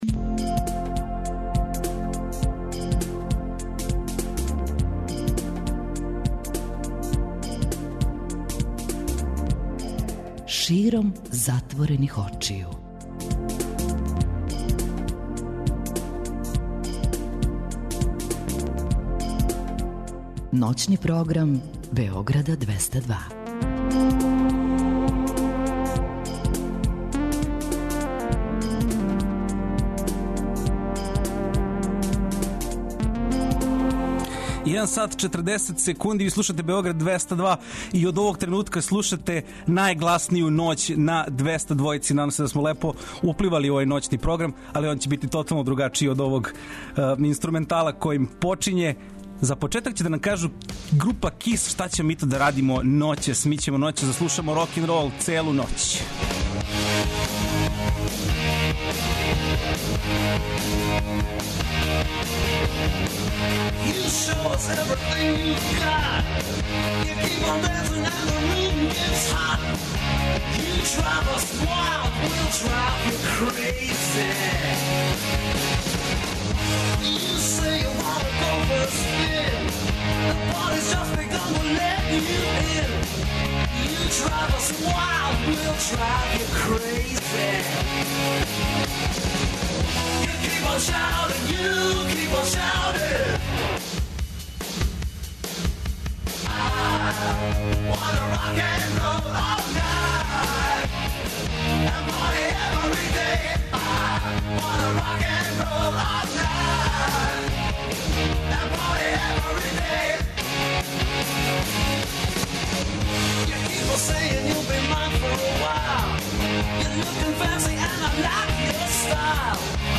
Овог пута слушаћете НАЈГЛАСНИЈУ НОЋ на 202! Не пропустите највеће хитове домаћег и страног блуза, рока и метала, а од 4ч слушаћемо најлепше рок баладе.